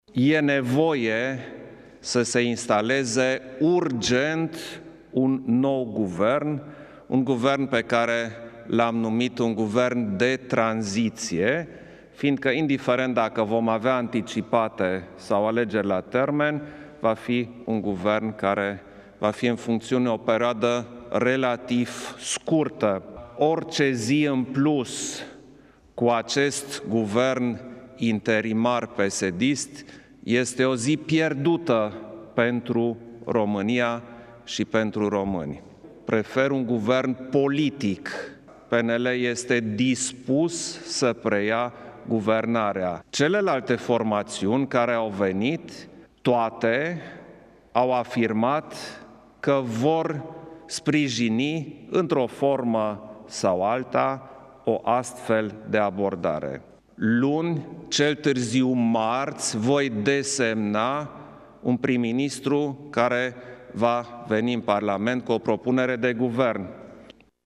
stiri-11-oct-declaratii-iohannis.mp3